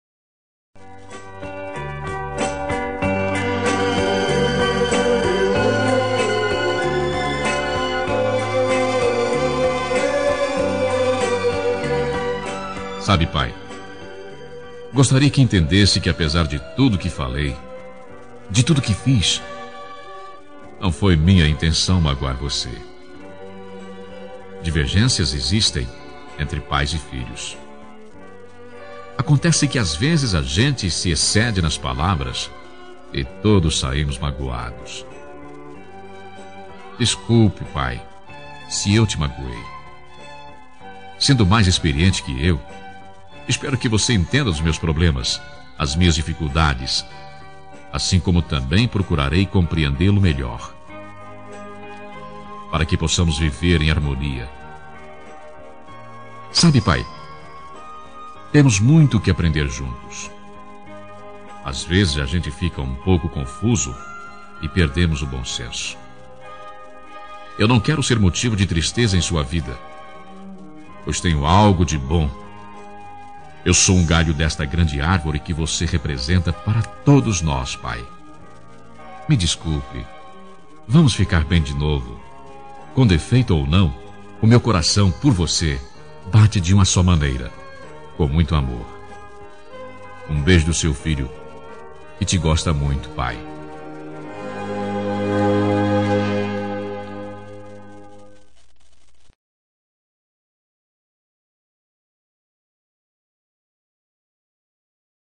Reconciliação Familiar – Voz Masculina – Cód: 088735 – Pai